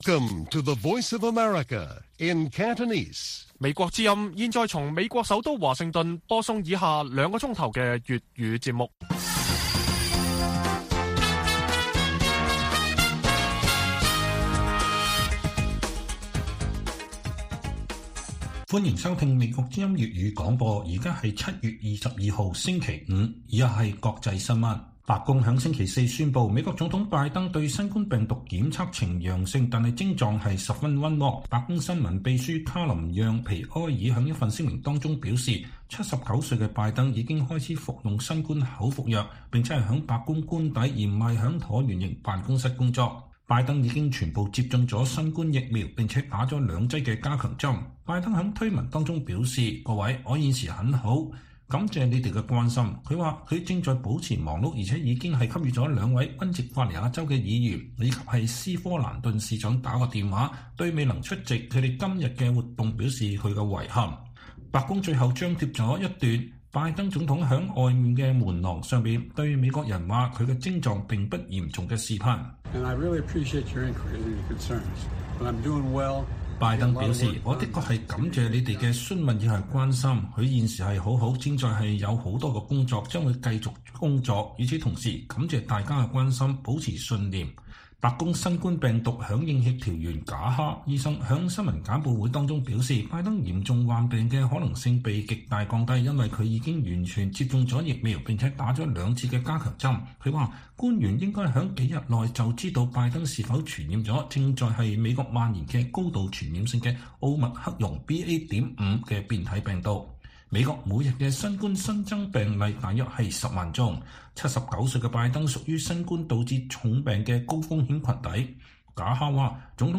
粵語新聞 晚上9-10點: 英國多個城市紀念香港721元朗白衣人無差別襲擊案三週年